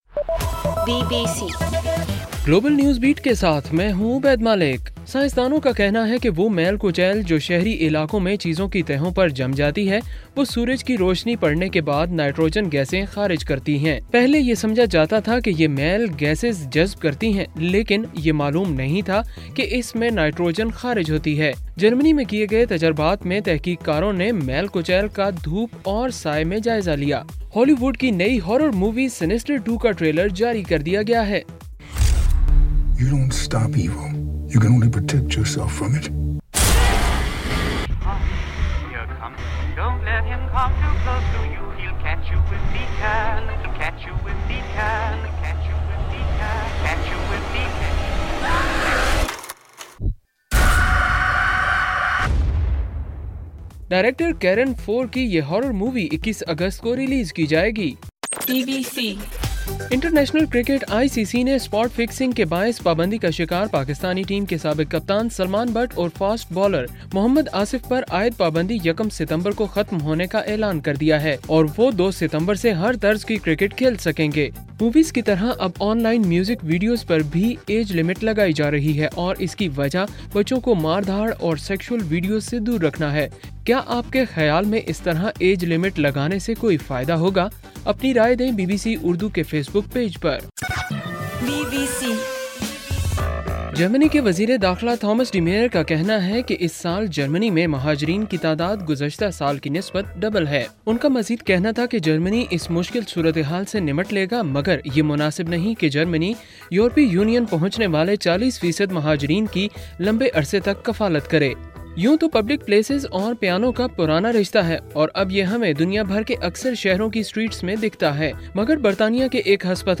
اگست 19: رات 12 بجے کا گلوبل نیوز بیٹ بُلیٹن